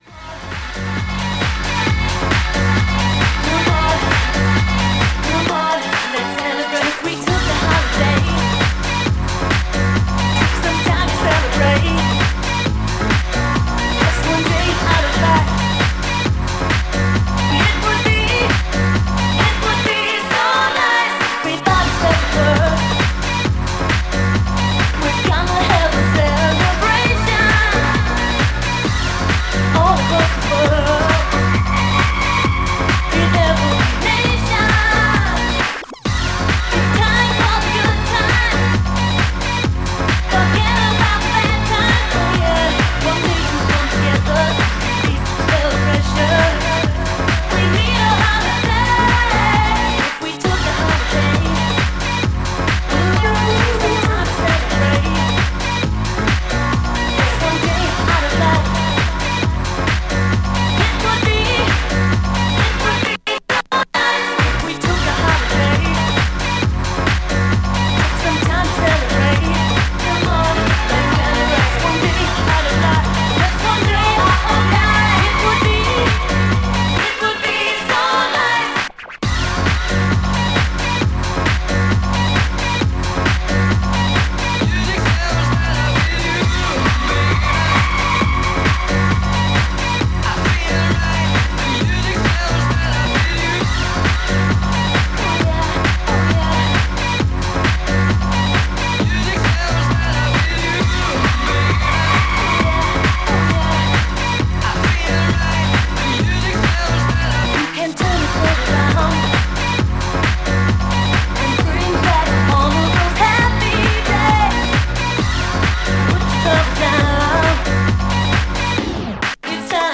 STYLE Acid / House / Hard House